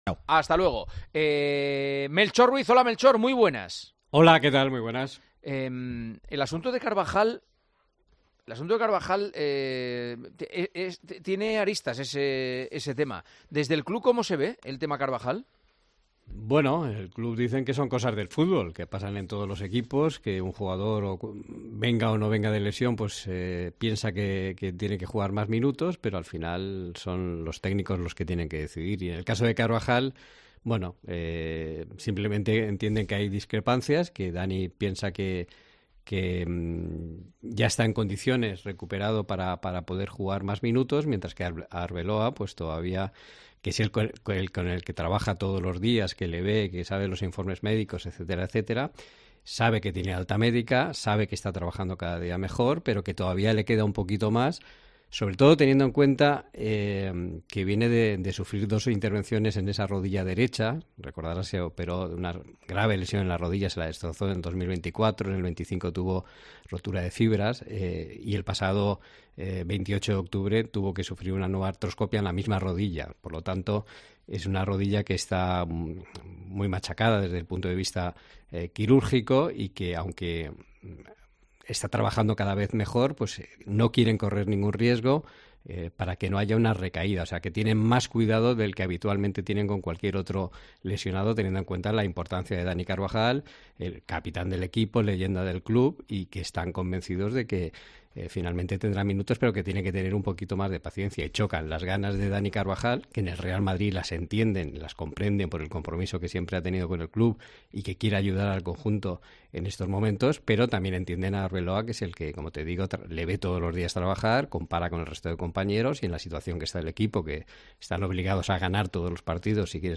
Juanma Castaño, director y presentador de El Partidazo de COPE
Durante el programa El Partidazo de COPE, Juanma Castaño y su equipo de colaboradores han analizado una situación que ha generado un choque de pareceres: mientras el jugador se siente en plenas condiciones para competir, el cuerpo técnico encabezado por Arbeloa opta por la prudencia.